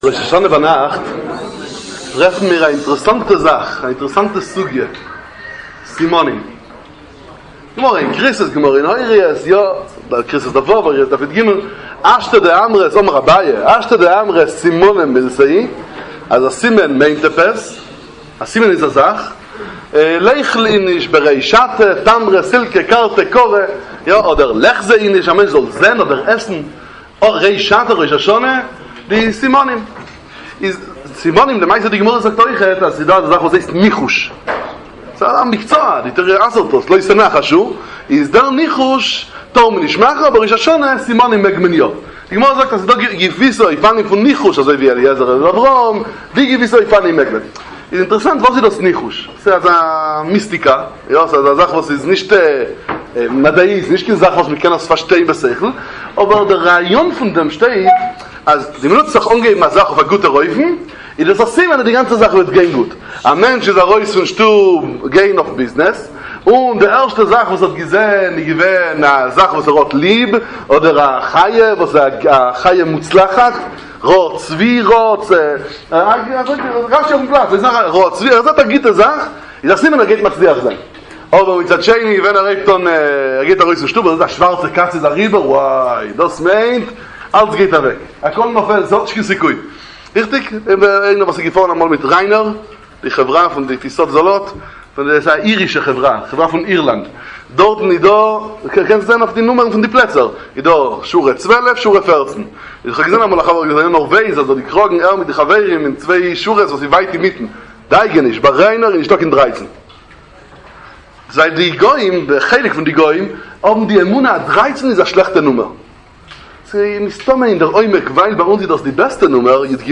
דרשת התעוררות והתחזקות לימי הרחמים